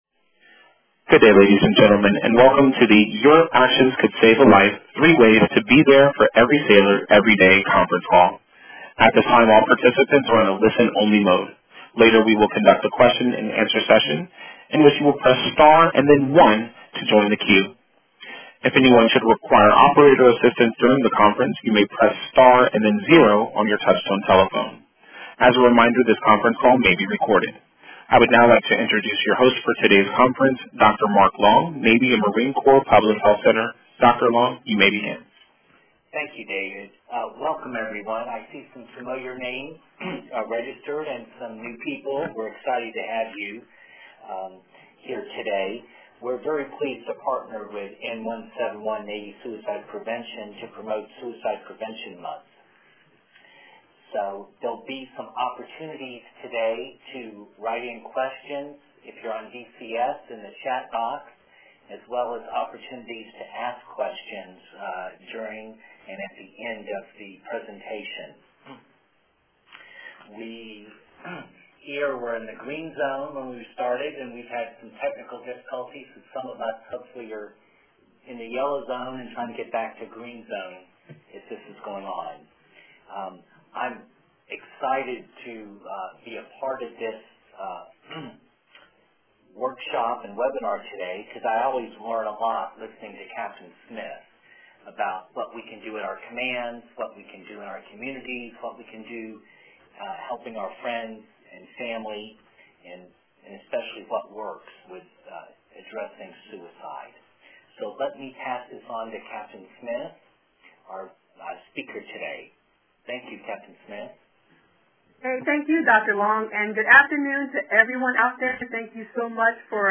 Webinars
Our webinars are approximately one hour long and are conducted online with an accompanying dial-in phone number.
6Sept_SuicidePreventionWebinar_Audio.mp3